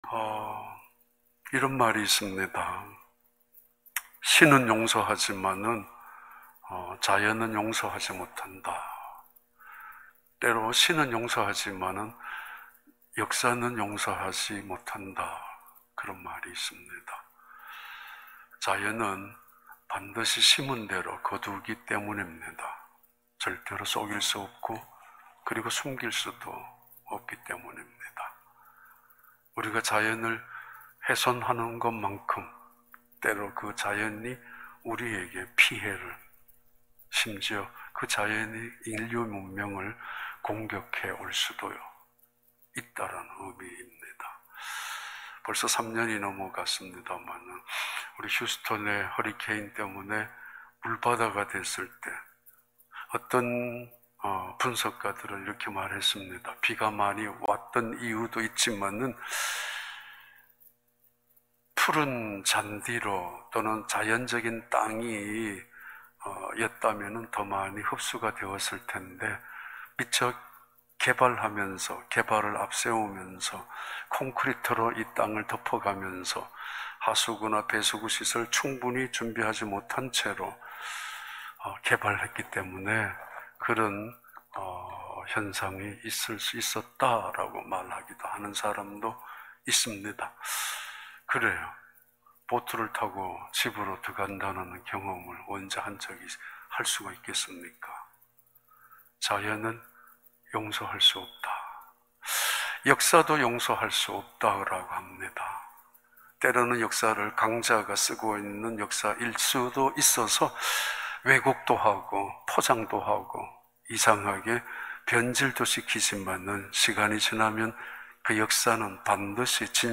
2021년 3월 30일 특별 새벽 예배6.mp3